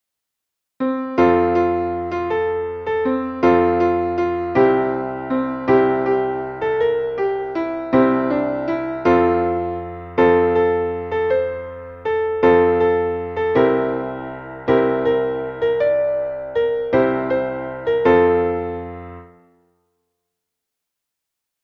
Traditionelles Winter- / Scherzlied